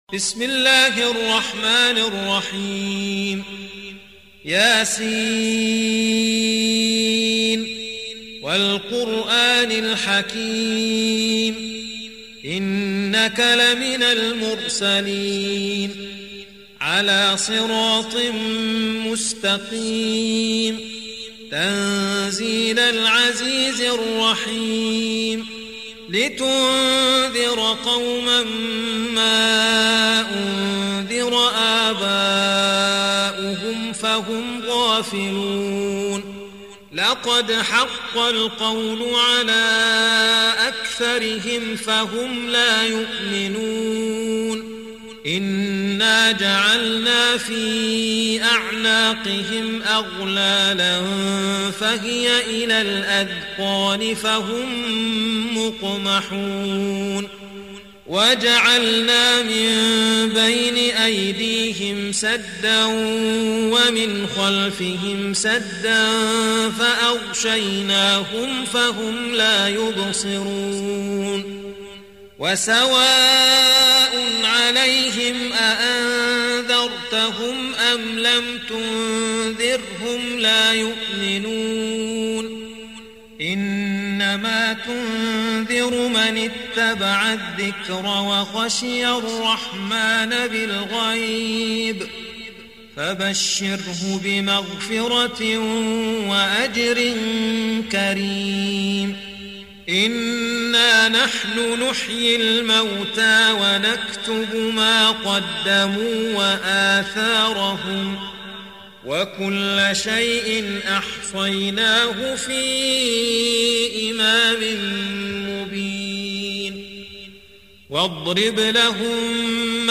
الاستماع للقرآن الكريم بصوت القارئ